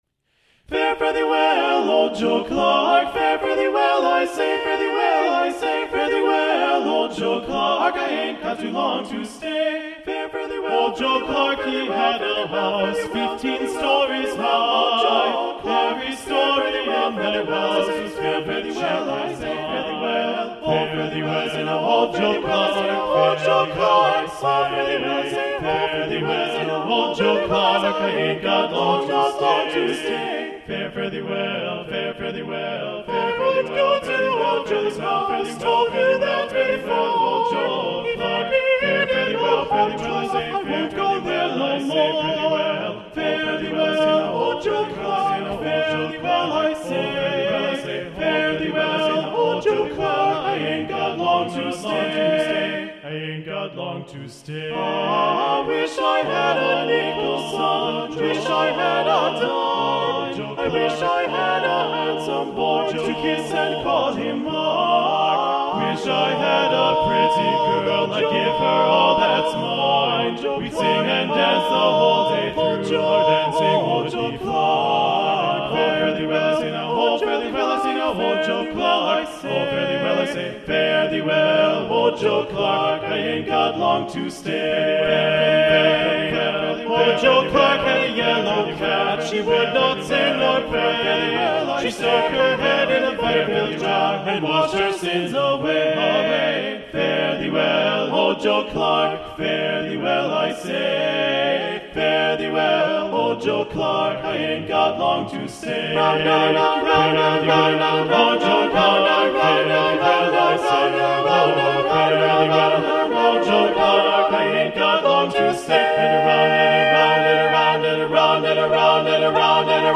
Choral Music
GENERAL MUSIC — A CAPPELLA